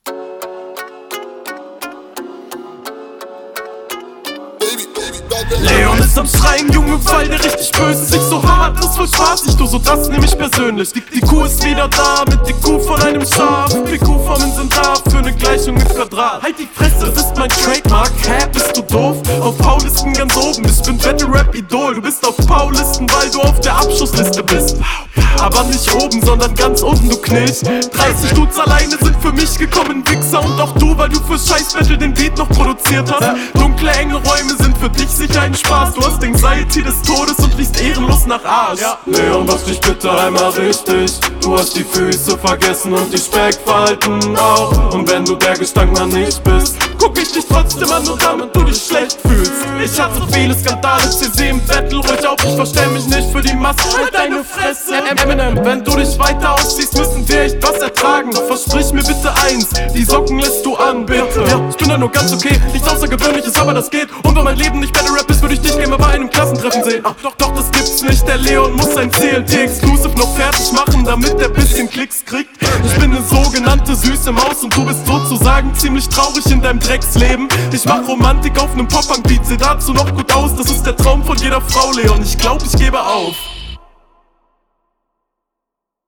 HR1: Stimme wirkt etwas leise im Vergleich zum Beat auch wenn ich dich durchgehend verstehen …